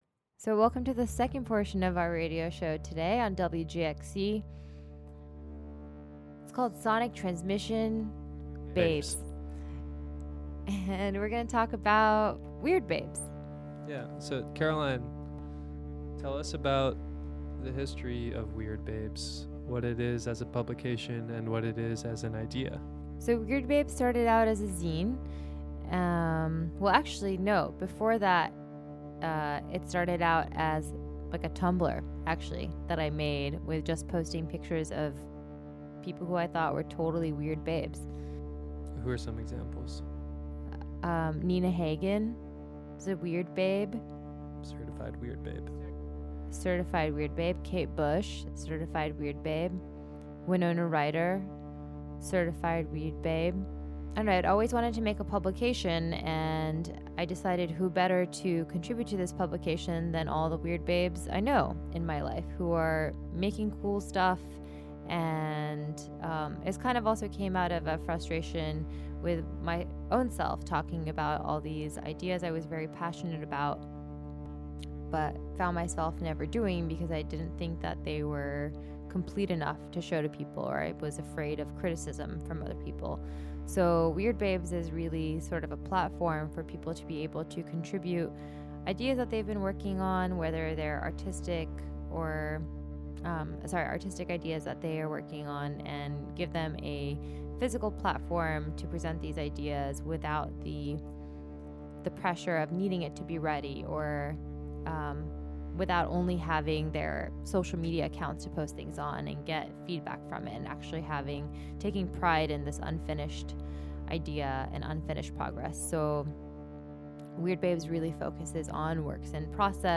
visit the Wave Farm airwaves for this broadcast. The second half of the program will focus on the first two cassette releases of Weird Babes, a zine that focuses on works-in-progress and works-in-process.